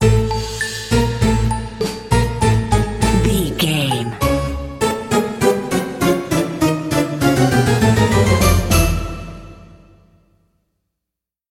Aeolian/Minor
D
orchestra
harpsichord
silly
circus
goofy
comical
cheerful
perky
Light hearted
quirky